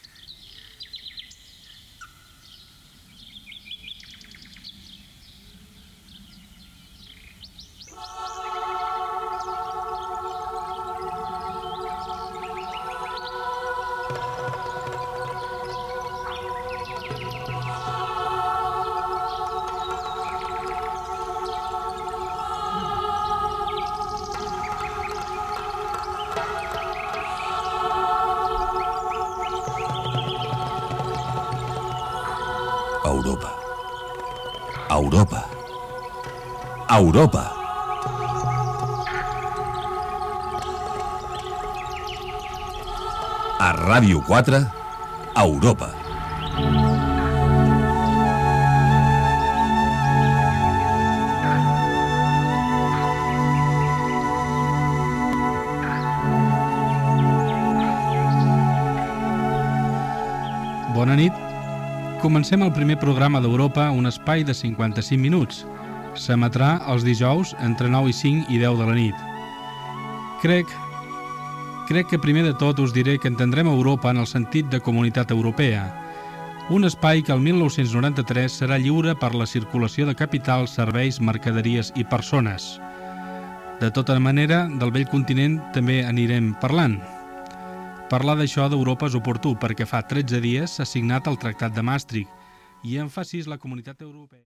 Careta i presentació del primer programa
Informatiu
FM